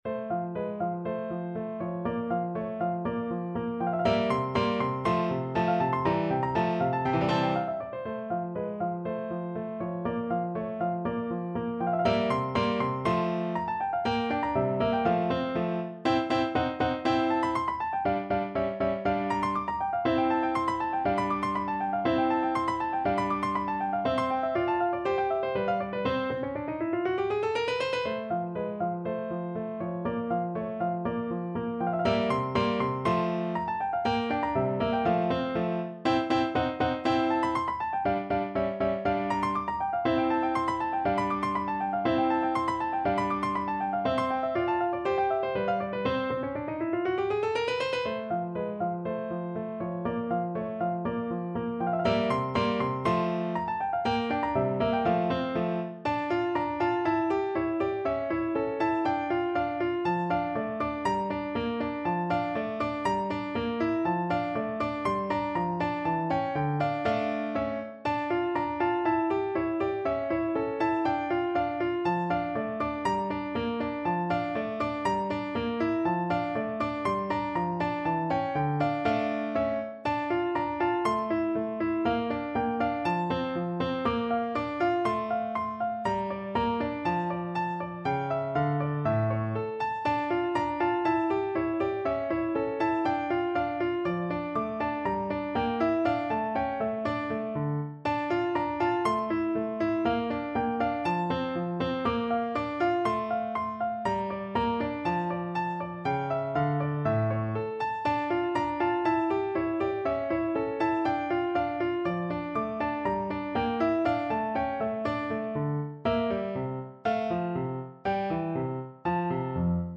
No parts available for this pieces as it is for solo piano.
Rondo - Allegro (View more music marked Allegro)
2/4 (View more 2/4 Music)
F major (Sounding Pitch) (View more F major Music for Piano )
Instrument:
Piano  (View more Intermediate Piano Music)
Classical (View more Classical Piano Music)
beethoven_sonatina_f_2nd_mvt_PNO.mp3